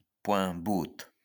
Pouembout (French pronunciation: [pwɛ̃but]